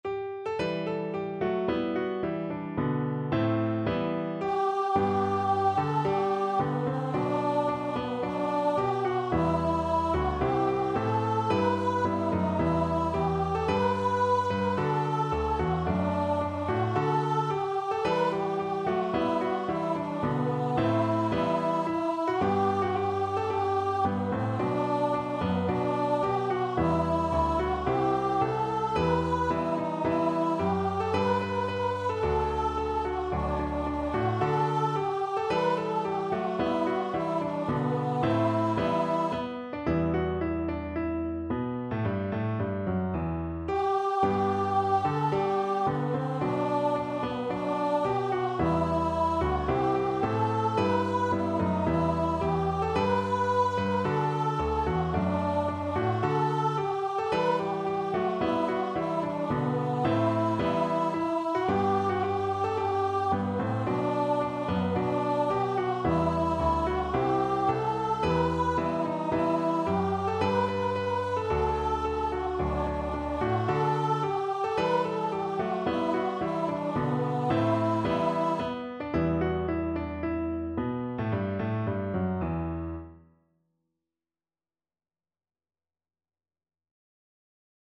4/4 (View more 4/4 Music)
~ = 110 Allegro (View more music marked Allegro)
Voice  (View more Easy Voice Music)
Traditional (View more Traditional Voice Music)